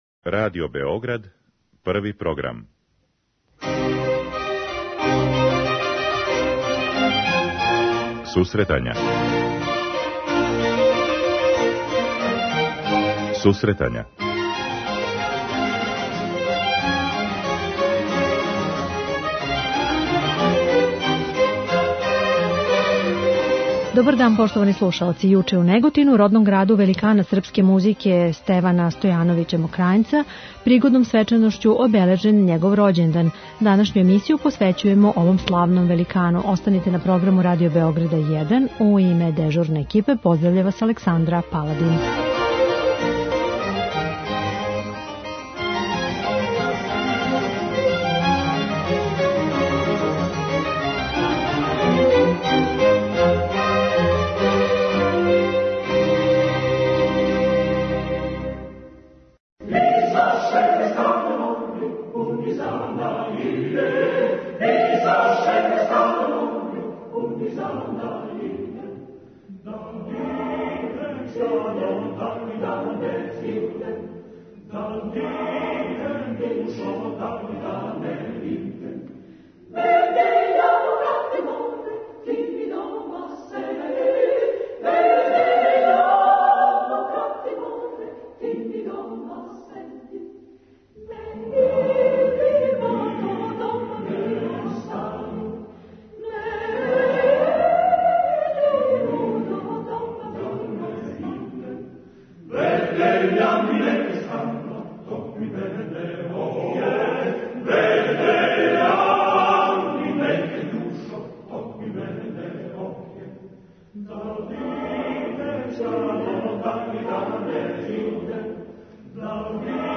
Јуче је у Неготину, родном граду великана српске музике, обележена годишњица рођења композитора Стевана Стојановића Мокрањца. Тим поводом данашња емисија посвећена је овом ствараоцу, а слушаоци ће бити у прилици да чују изводе из различитих текстова који су му посвећени.